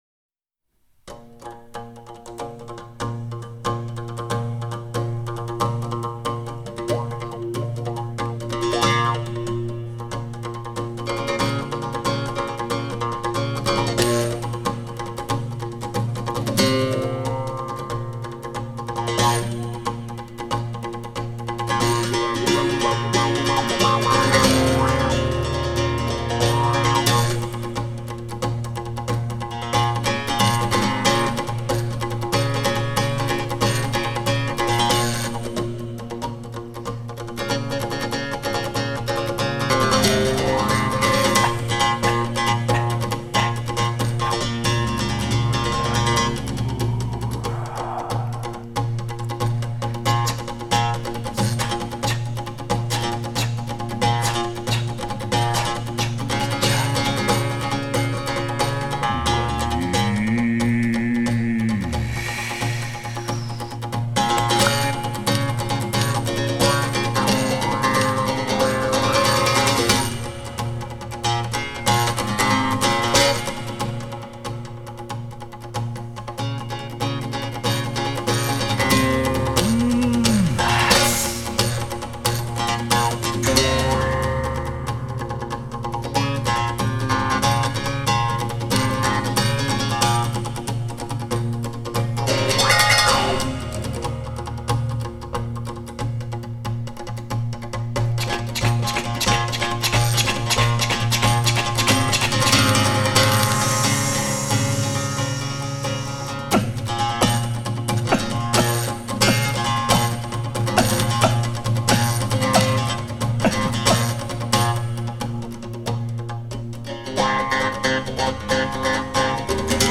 Genre: World Music
Recording: Windwood Studios